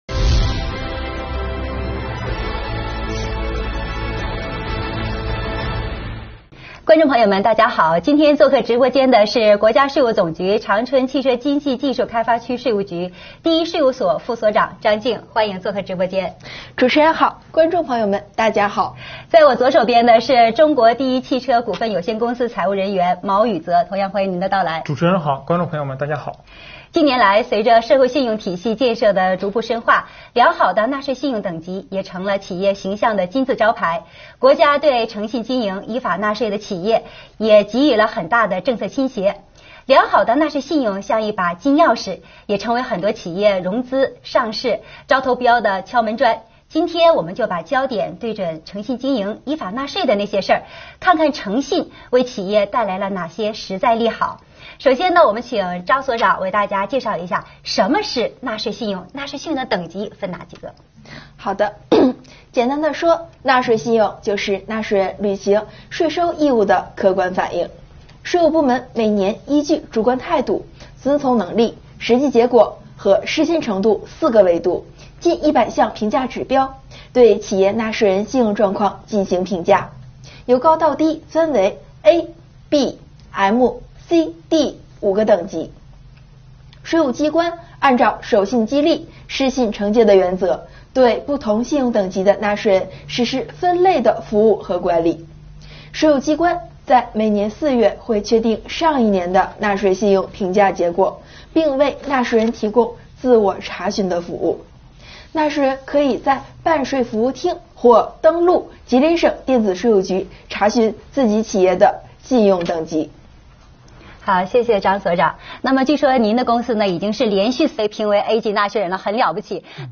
2021年第26期直播回放：信用纳税促发展 春风助企共前行——长春市税务局诚信纳税系列宣传之一